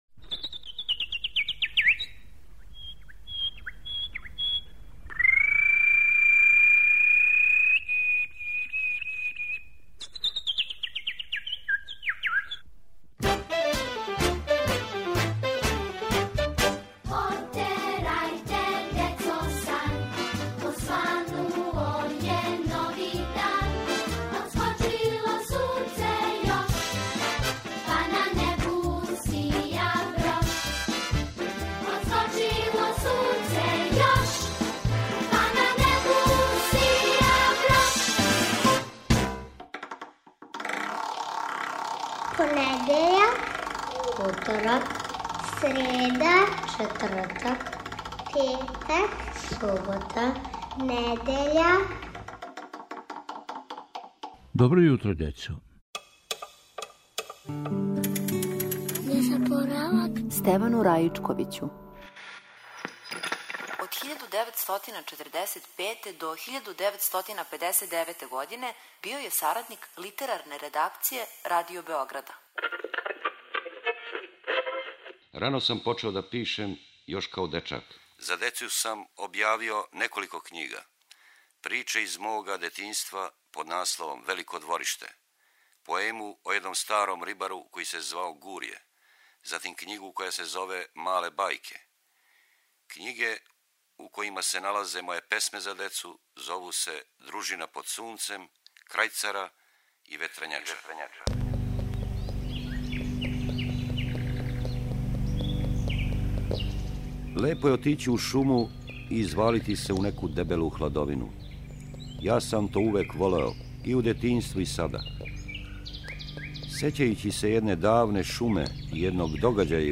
Недељом у мају слушате поезију Стевана Раичковића.